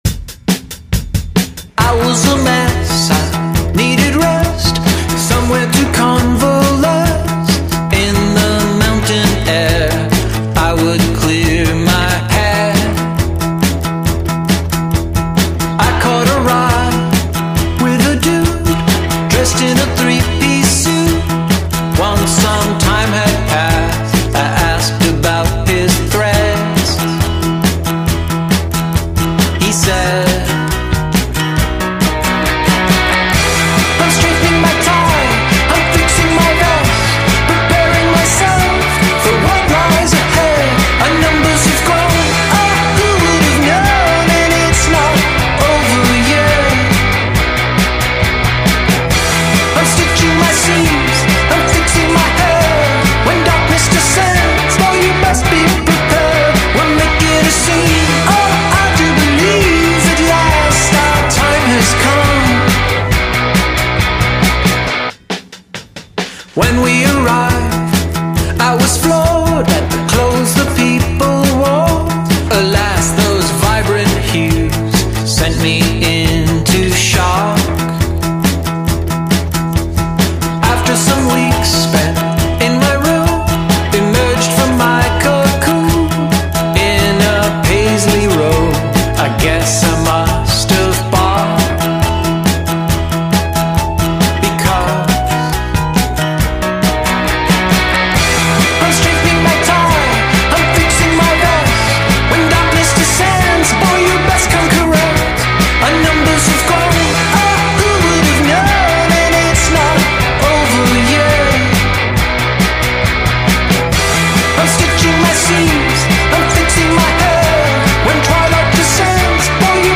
rock band